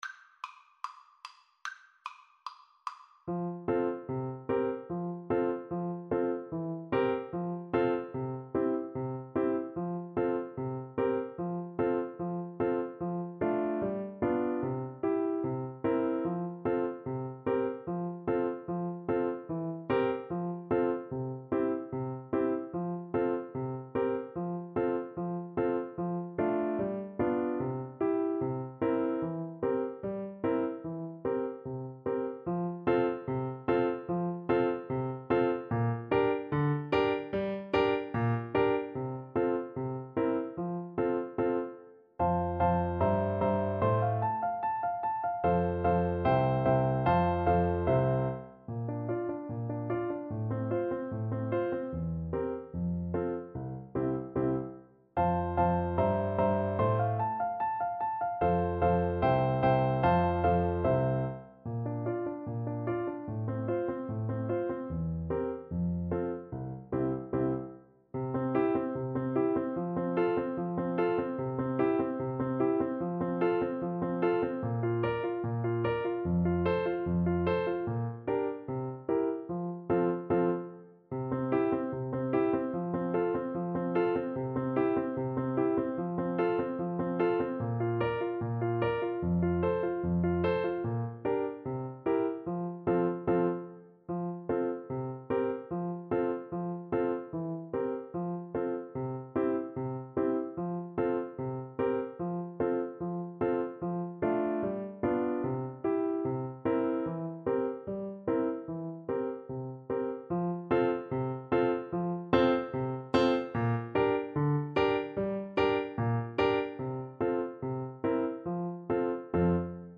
Allegretto = 74
Classical (View more Classical Tuba Music)